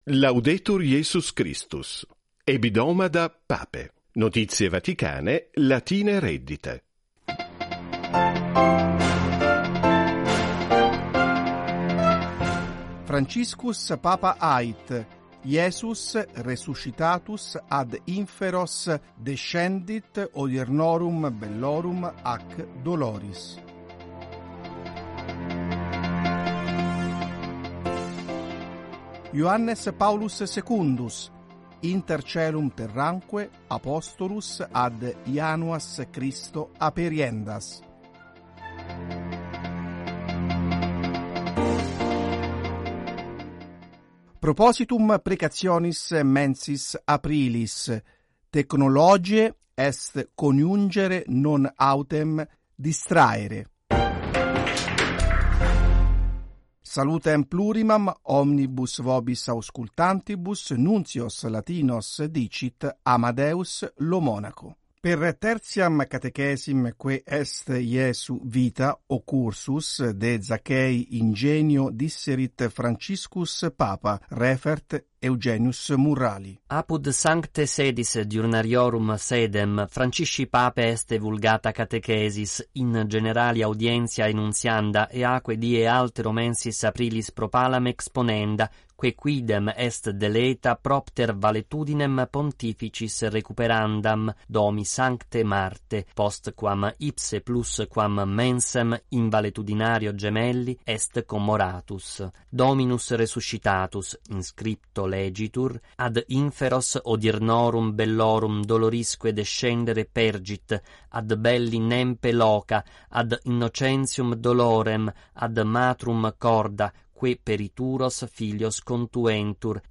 Vatican Radio News in Latin 23 subscribers updated 6d ago Subscribe Subscribed Play Playing Share Mark all (un)played …